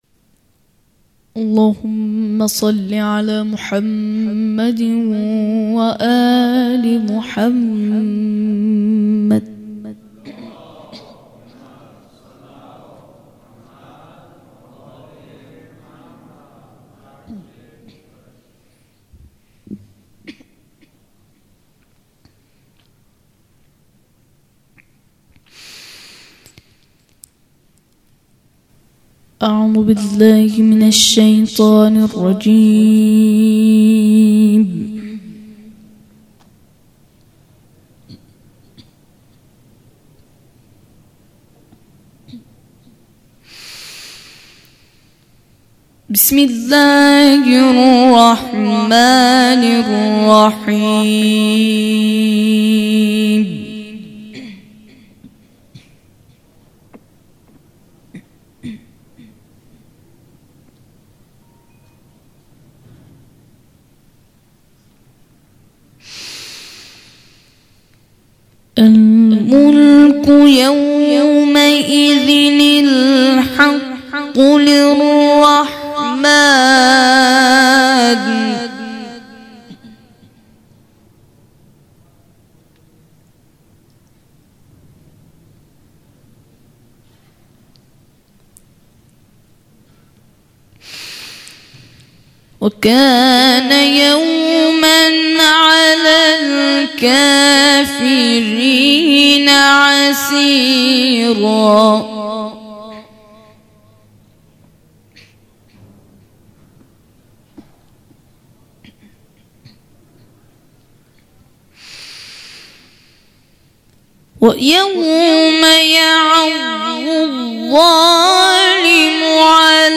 0 0 قرائت مجلسی قاری